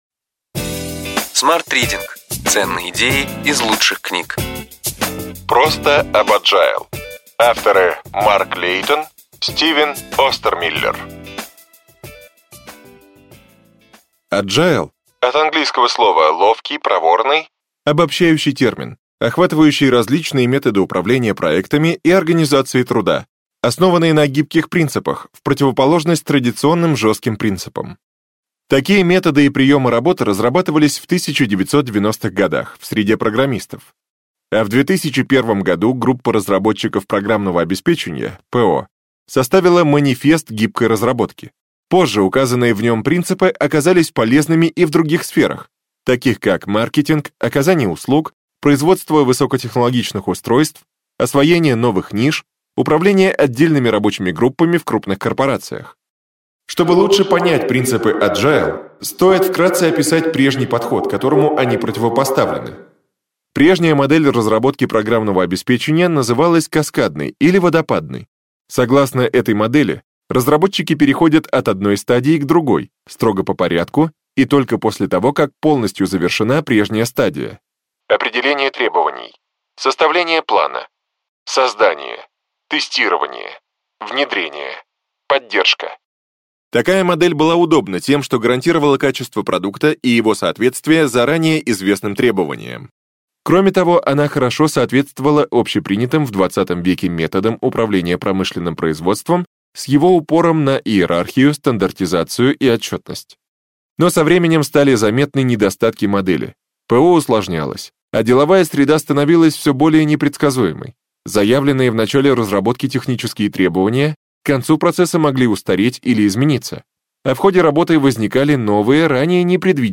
Аудиокнига Ключевые идеи книги: Просто об Agile.